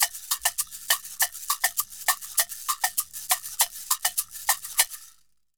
Caxixi_Samba 100_1.wav